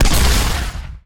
weapon_mortar_003.wav